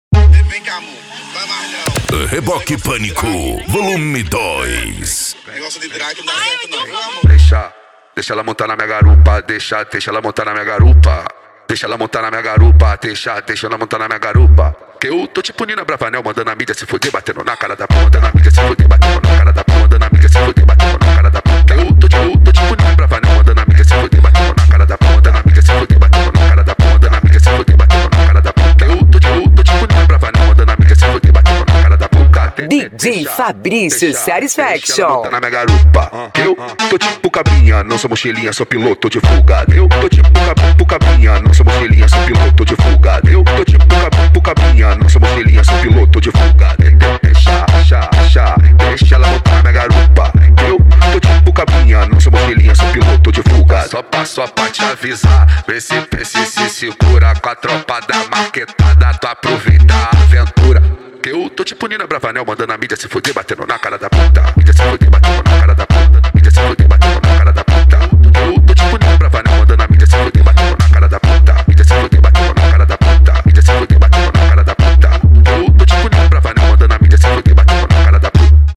Bass
Funk
Mega Funk